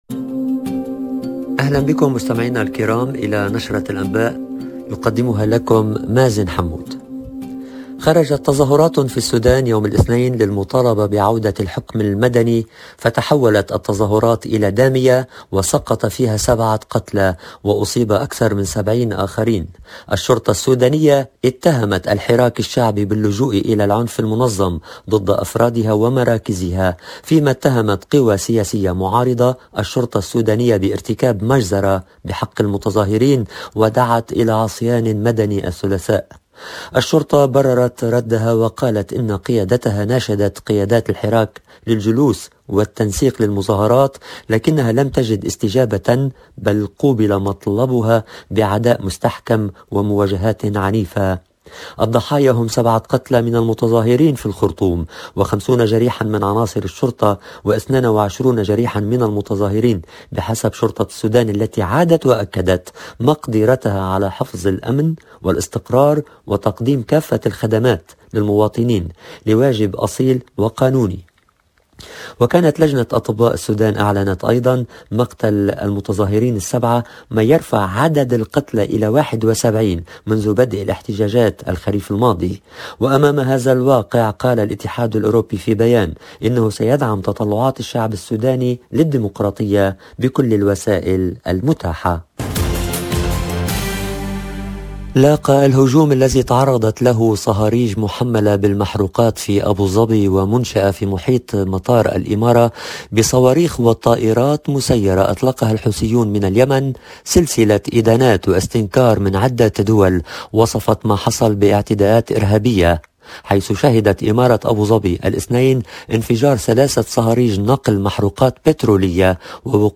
LE JOURNAL DU SOIR EN LANGUE ARABE DU 18/01/22